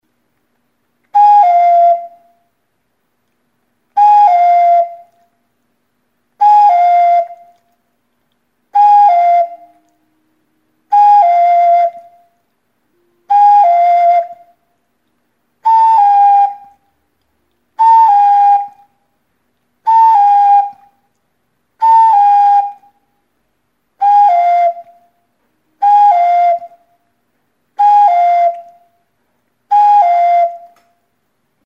CUCO; Flauta | Soinuenea Herri Musikaren Txokoa
Aerophones -> Flutes -> Ocarina
Recorded with this music instrument.
Okarina gisako flauta da. Nota aldatzeko 2 zulo ditu.
CLAY; CERAMICS